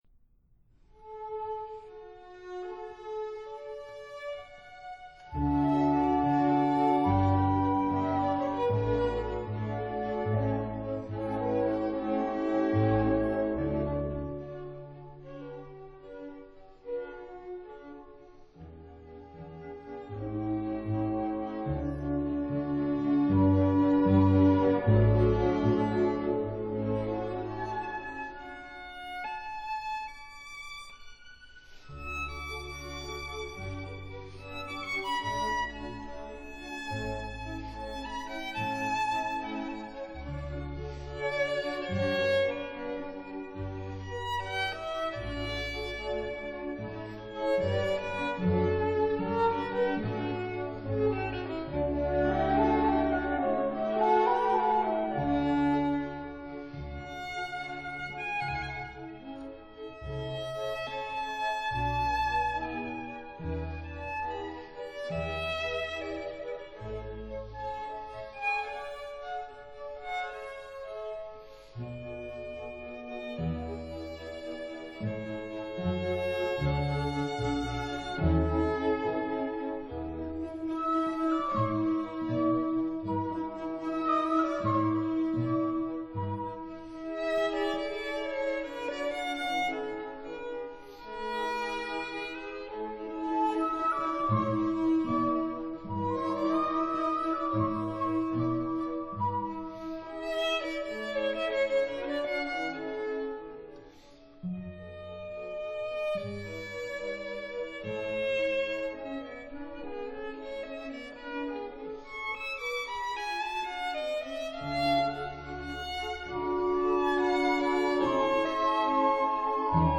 這一首協奏曲共3個樂章，樂器編制爲主奏小提琴與雙簧管2、圓號2、弦樂5聲部。